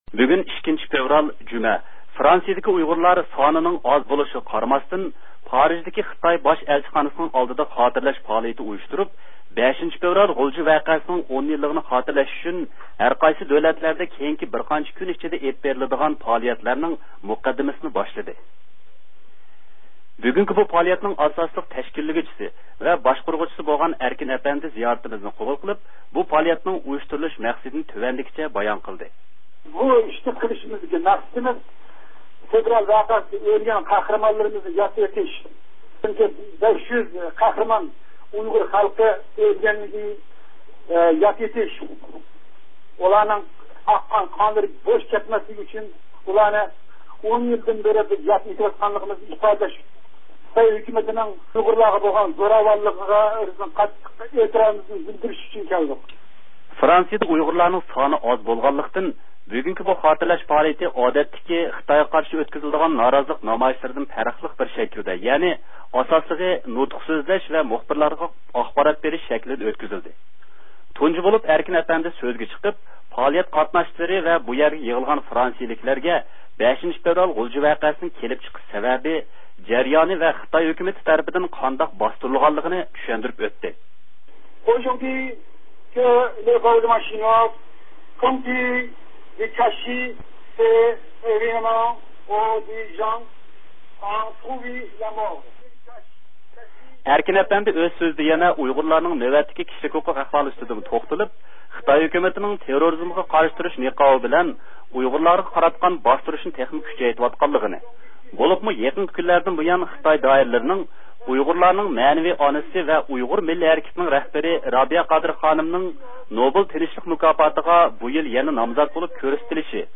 بۈگۈن 2-فەۋرال، جۈمە فرانسىيىدىكى ئۇيغۇرلار، سانىنىڭ ئاز بولىشىغا قارىماستىن، پارىژدىكى خىتاي باش ئەلچىخانىسىنىڭ ئالدىدا خاتىرلەش پائالىيىتى ئۇيۇشتۇرۇپ، «5-فەۋرال غۇلجا ۋەقەسى» نىڭ 10 يىللىقىنى خاتىرلەش ئۈچۈن ھەرقايسى دۆلەتلەردە كېيىنكى بىرقانچە كۈن ئىچىدە ئەپ بېرىلىدىغان پائالىيەتلەرنىڭ مۇقەددىمىسىنى باشلىدى.